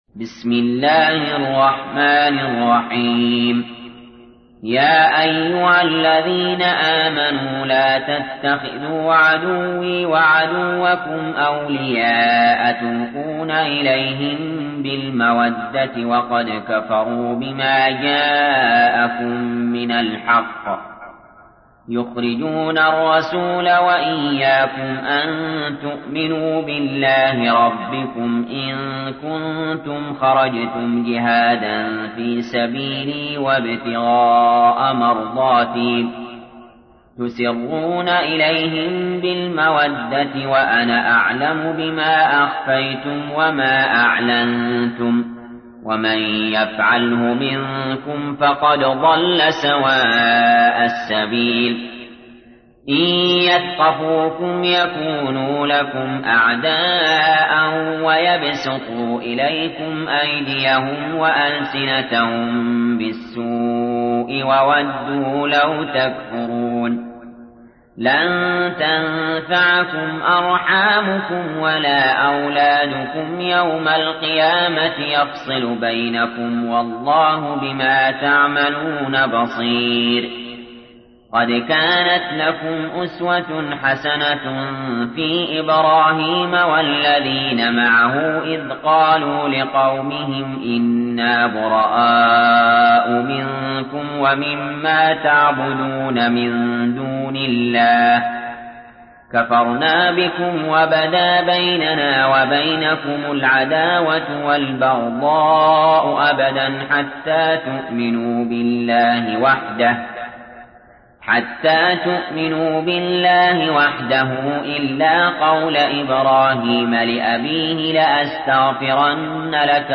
تحميل : 60. سورة الممتحنة / القارئ علي جابر / القرآن الكريم / موقع يا حسين